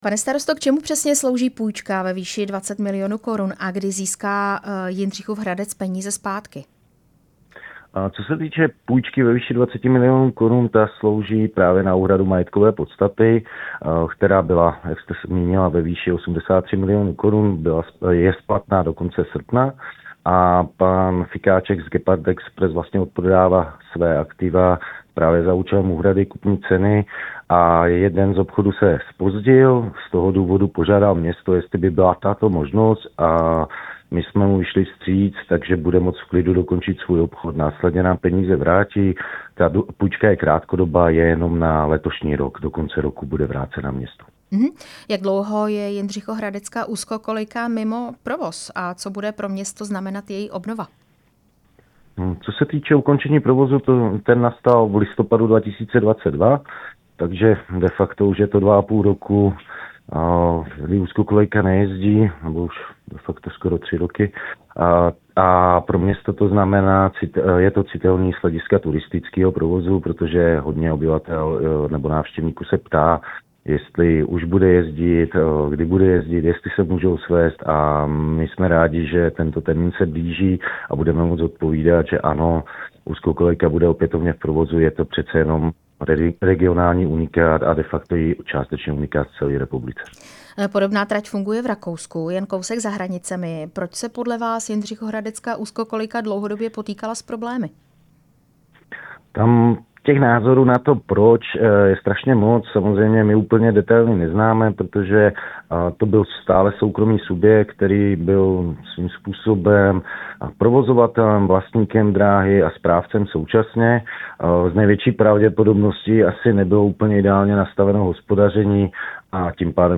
Trať na začátku léta koupila brněnská společnost Gepard Expres za 83 milionů Kč v insolvenčním řízení. Ve vysílání Radia Prostor jsme se na úzkokolejku ptali starosty Jindřichova Hradce Michala Kozára z hnutí ANO.
Rozhovor se starostou Jindřichova Hradcem Michalem Kozárem